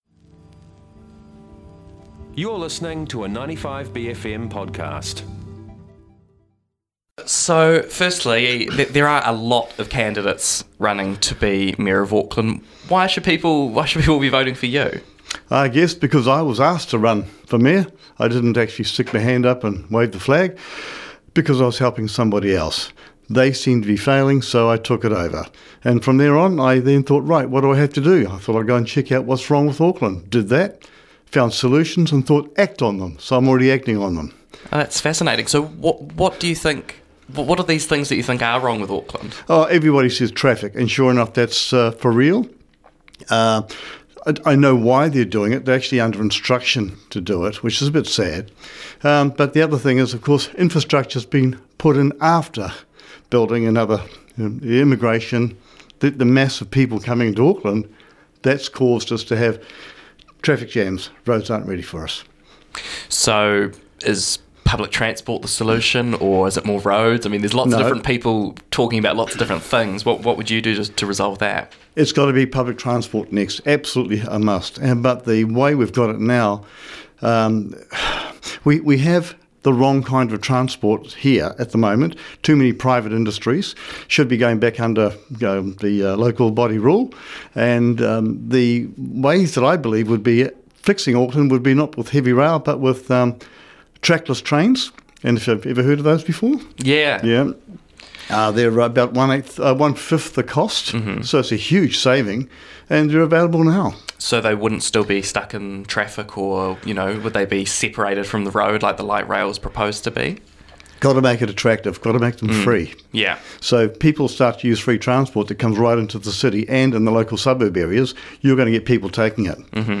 *NOTE* This is the full version of the interview.